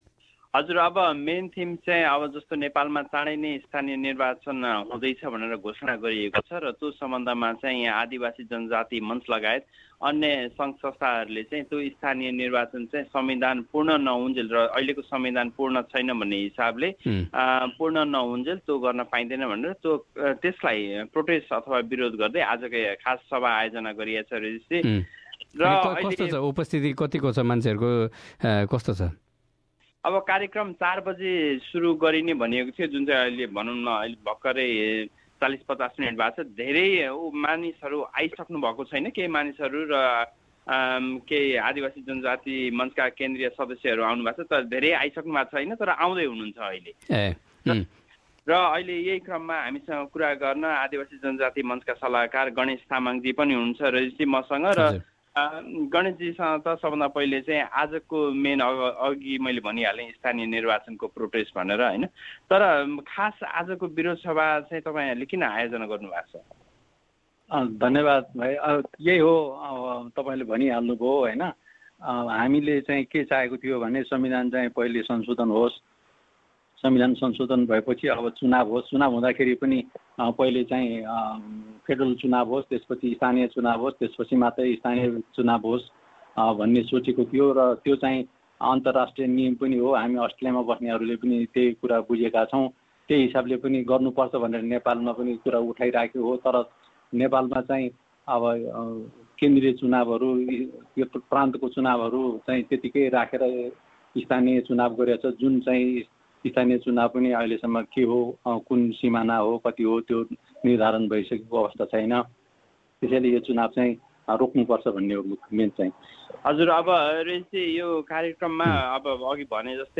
आफ्नो निधारमा कालोपट्टी बाँधेका उक्त बिरोध सभाका सहभागीहरुले चुनाव भन्दा अगाडी परिमार्जन सहित संबिधान संसोधन संसदमा पारीत गर्न नेपाली राजनीतिज्ञहरु समक्ष आग्रह पनि गरेका थिए। थप रिपोर्ट